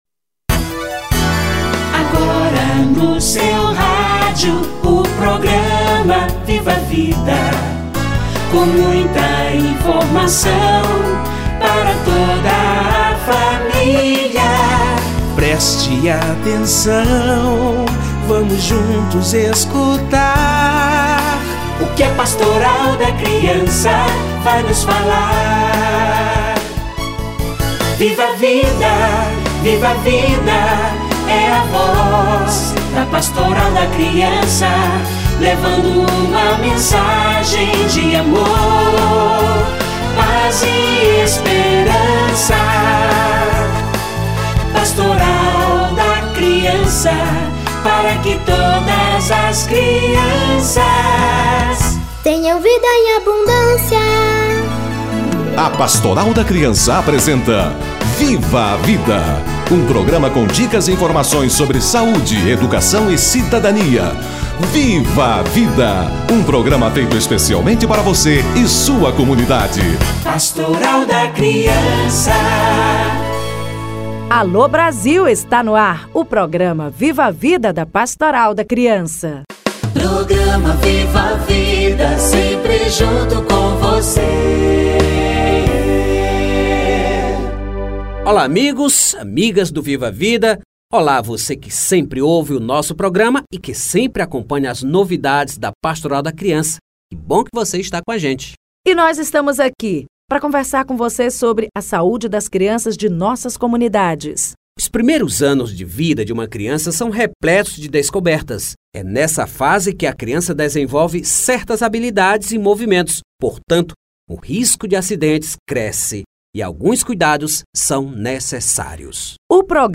Acidentes na infância - Entrevista